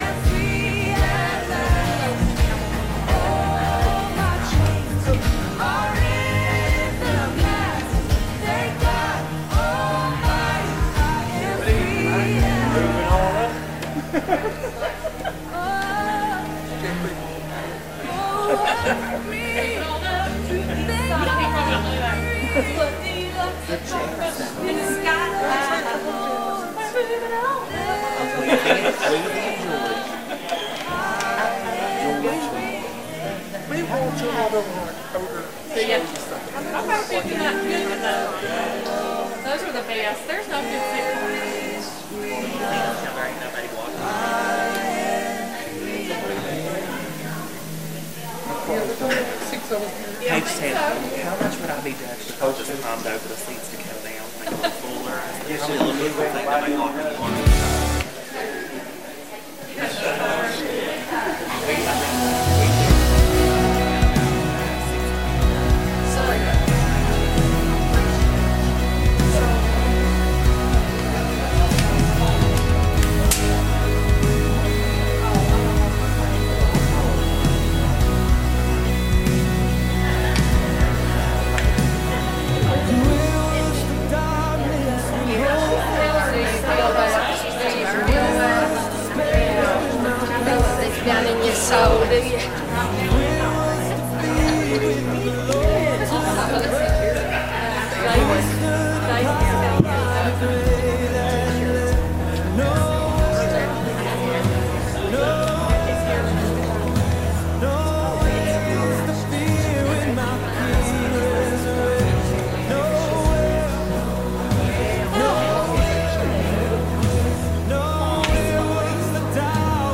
Revival